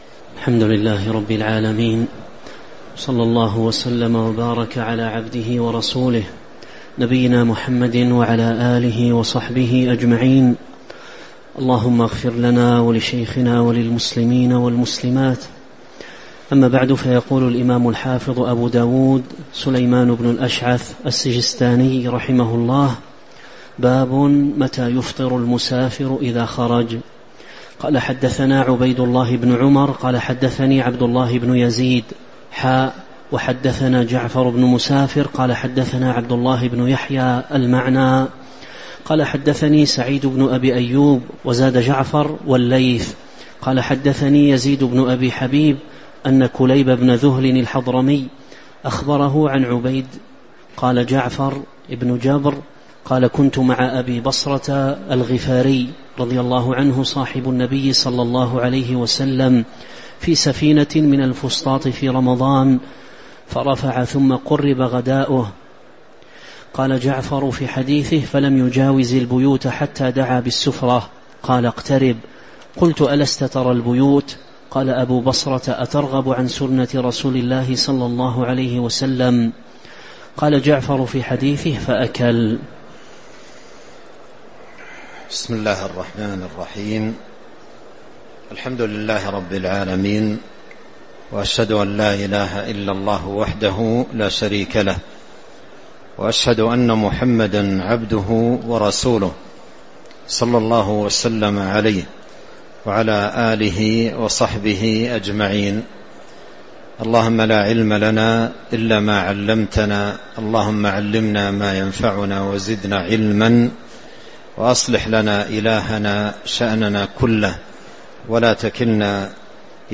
تاريخ النشر ١٥ رمضان ١٤٤٦ هـ المكان: المسجد النبوي الشيخ